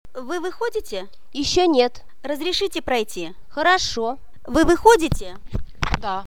Kuuntele keskustelu bussissa ja sitten allaoleva kysymys.